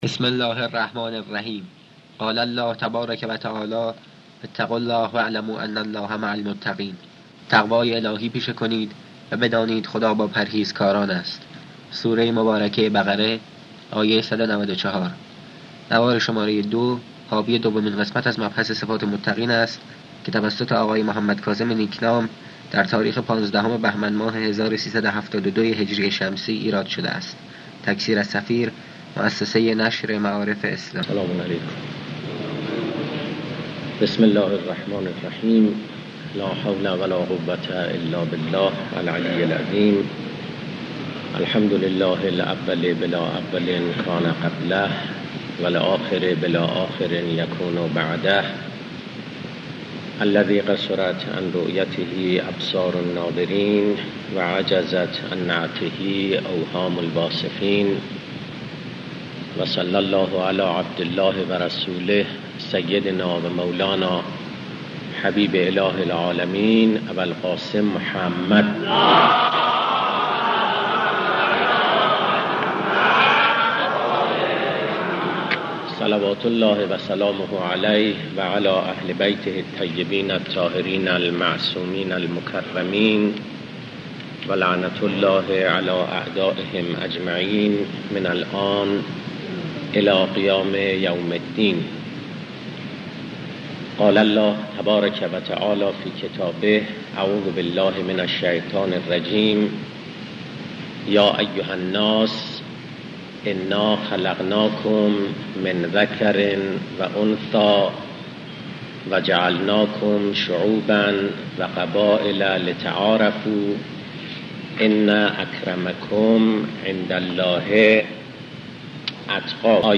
سخنرانی اخلاقی